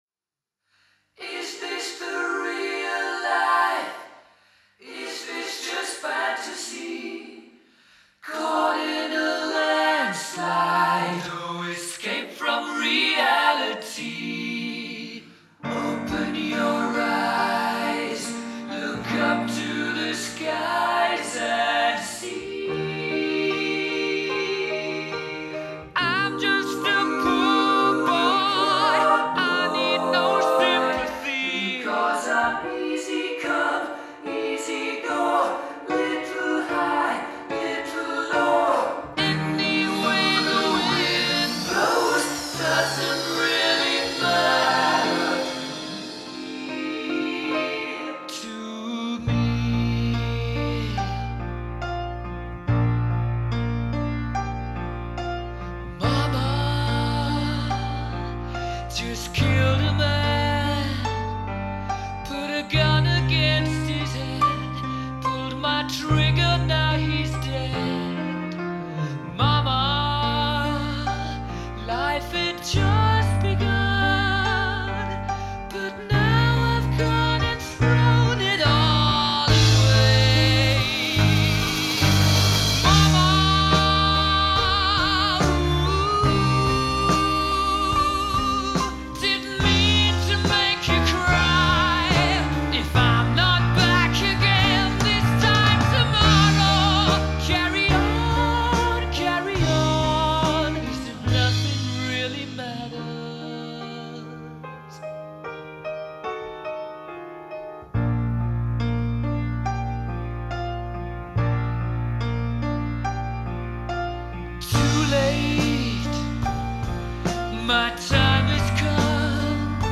3D Binaural Hörbeispiele